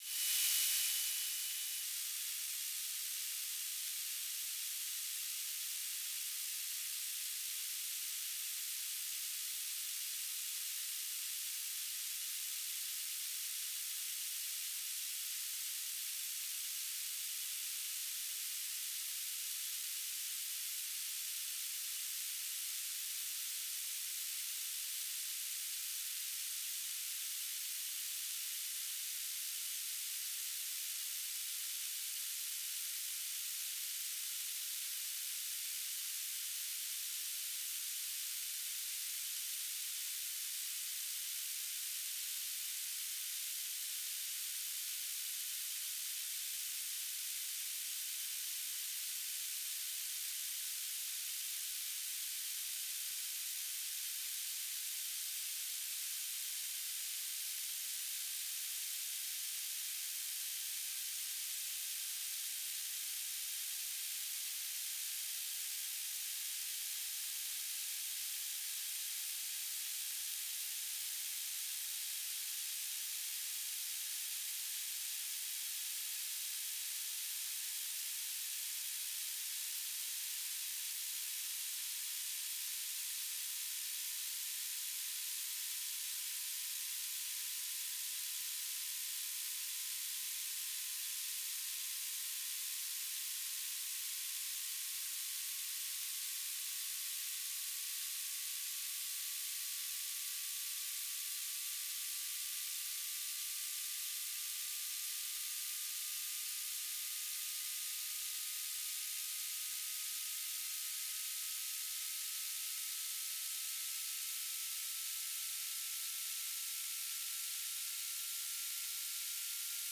"transmitter_description": "Beacon",
"transmitter_mode": "BPSK",